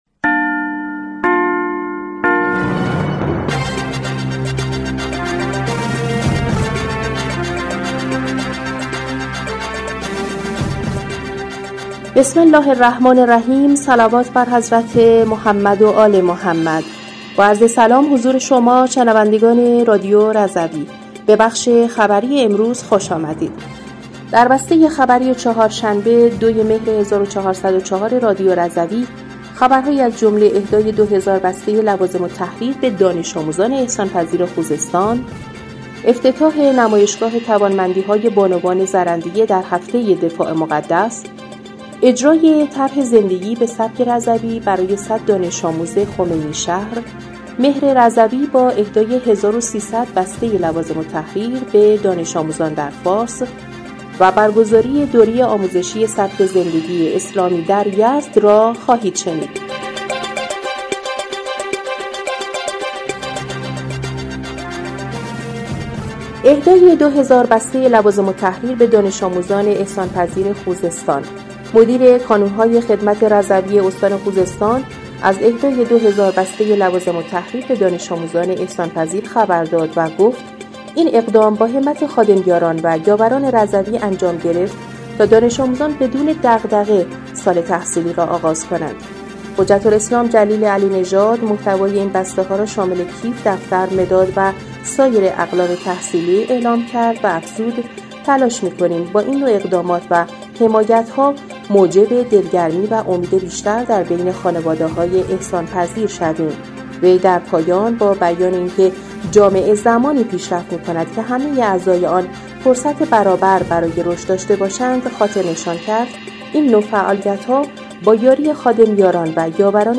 بسته خبری ۲ مهر ۱۴۰۴ رادیو رضوی/